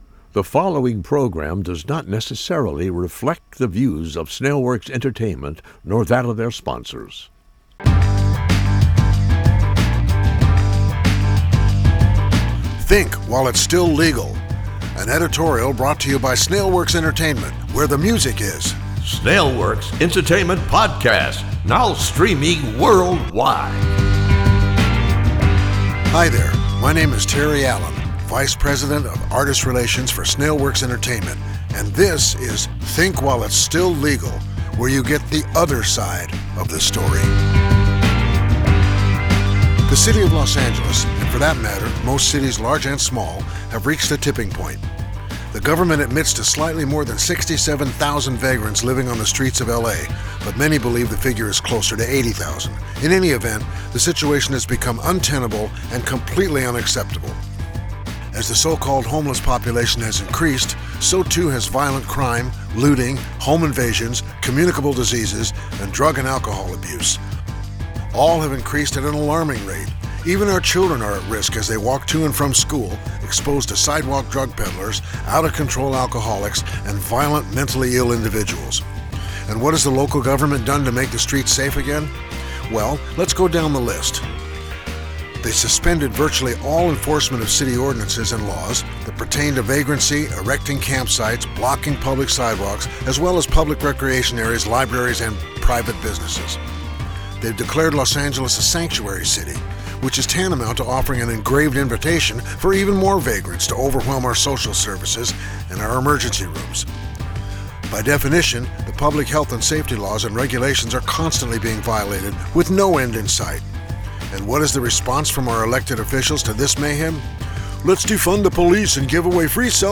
Comtemporary country rock at its best.